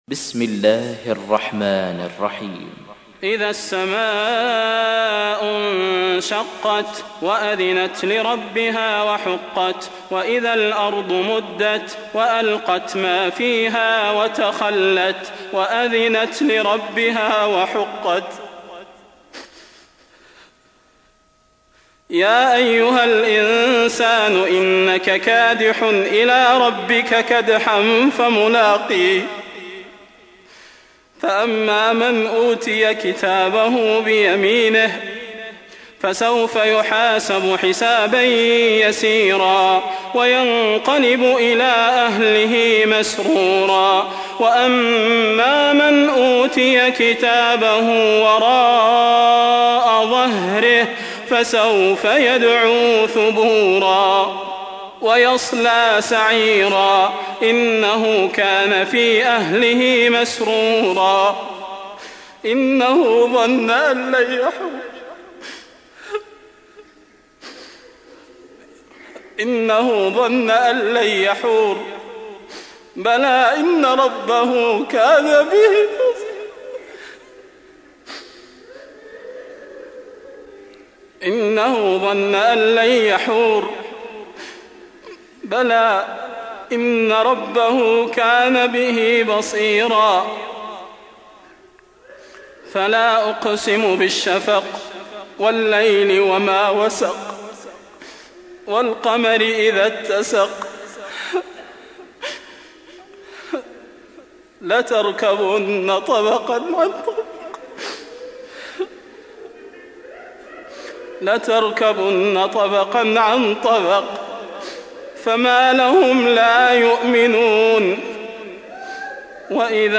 تحميل : 84. سورة الانشقاق / القارئ صلاح البدير / القرآن الكريم / موقع يا حسين